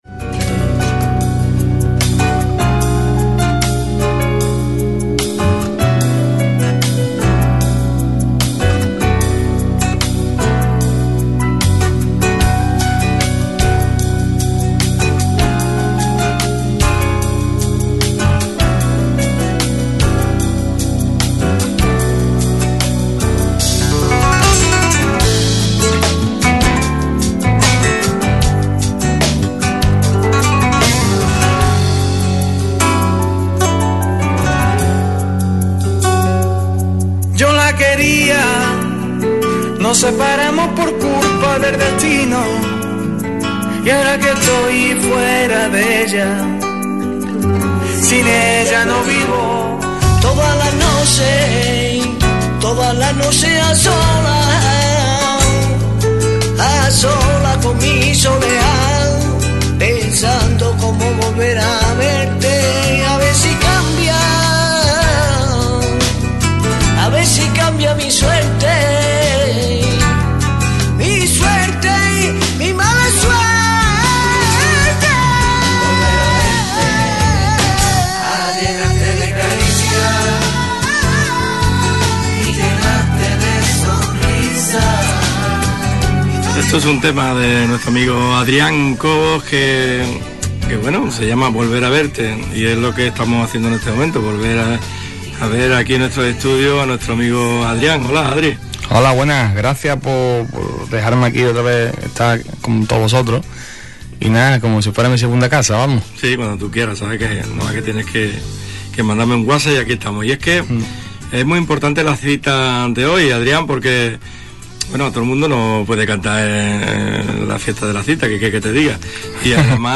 disfrutamos de su compañía en directo.